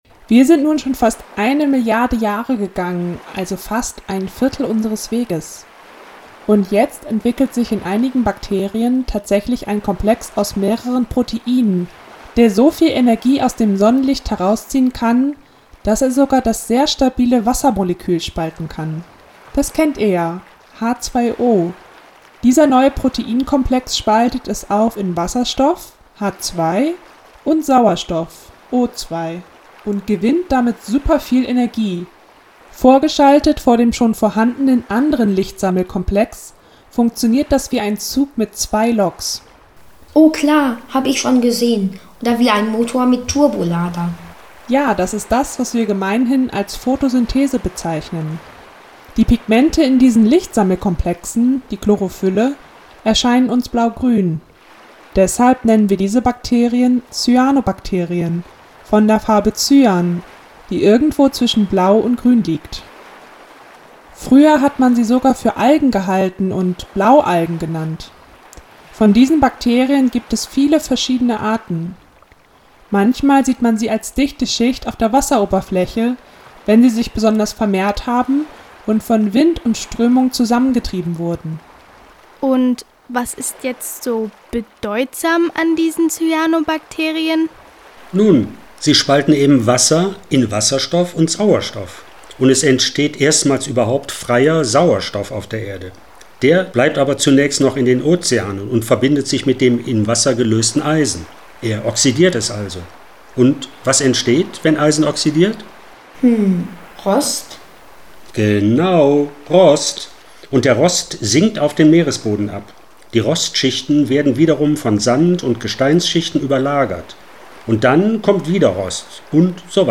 Diese Station im Hörspiel: